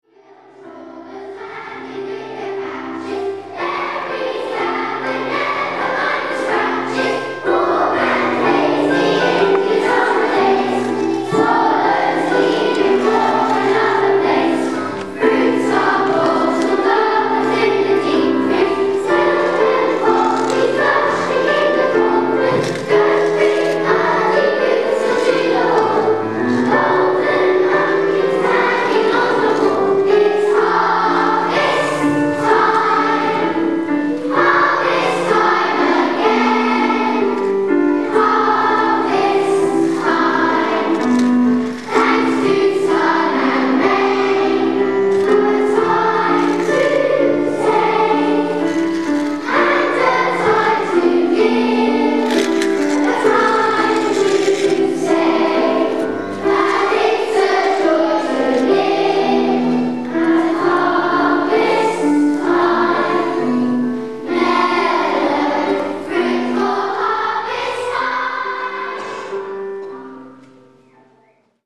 Harvest Festival at Loders Church
The whole school then sang 'Michaelmas Daisies'